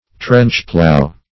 Search Result for " trench-plow" : The Collaborative International Dictionary of English v.0.48: Trench-plow \Trench"-plow"\, Trench-plough \Trench"-plough`\ (-plou`), v. t. To plow with deep furrows, for the purpose of loosening the land to a greater depth than usual.